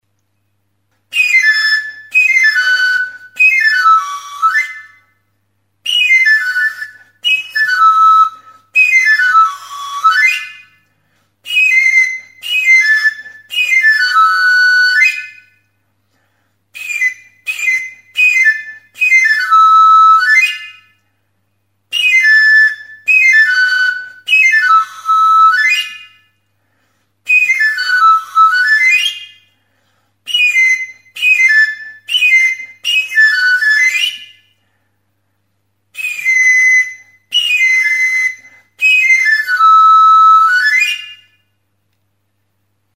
Recorded with this music instrument.
PITO DE AFILADOR; PITO DE CASTRADORES; Flauta de Pan
Aerophones -> Flutes -> Pan flute
Ezpelezko taulatxo sasi-trapezoidal batean neurri ezberdinetan egindako 11 tutuz osatua dago.